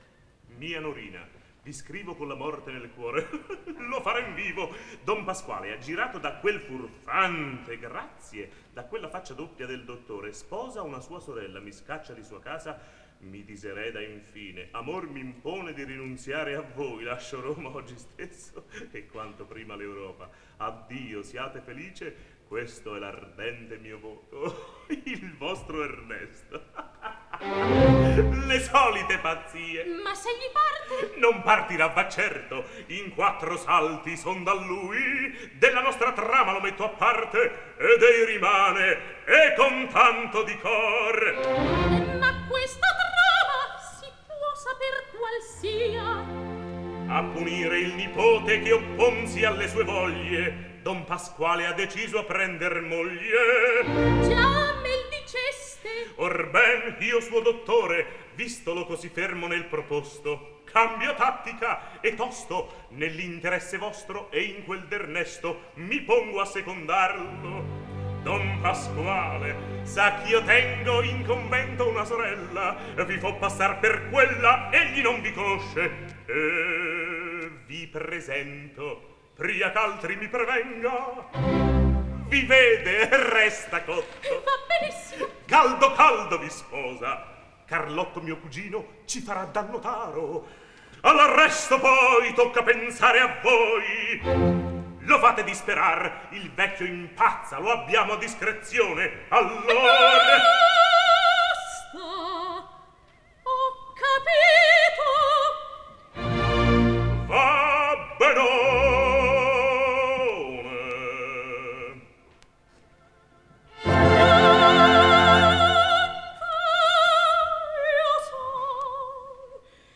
Сцена и дуэт Норины и доктора Малатесты - М.Ринальди , Р.Панераи